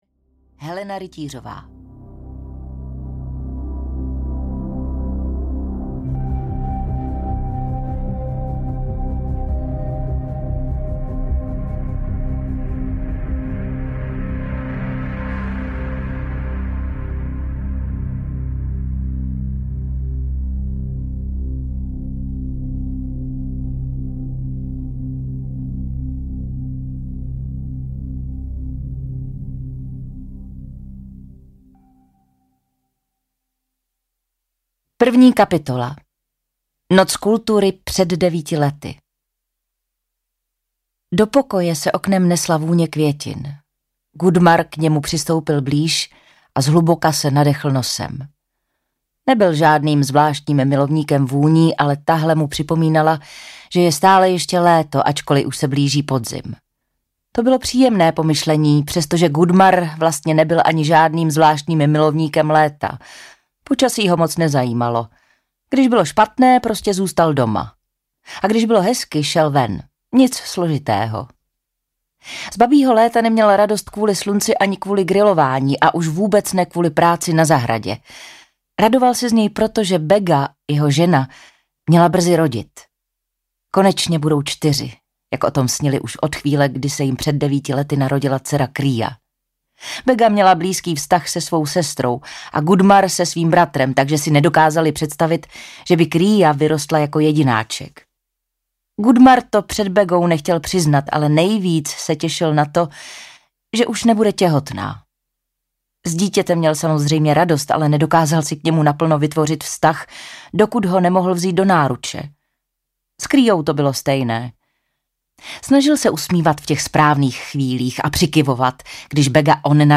Temná noc audiokniha
Ukázka z knihy
• InterpretKlára Cibulková